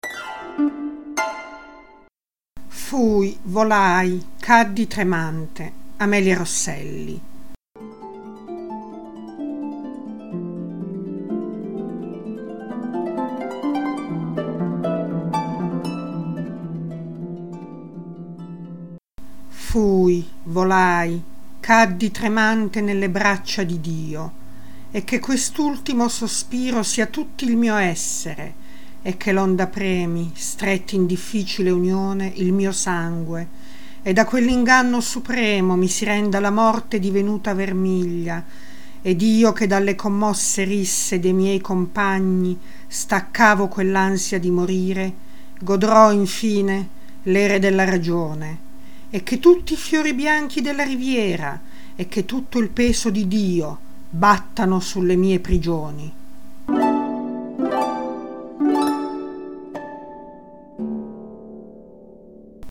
Suoni poetici » Poesie recitate da artisti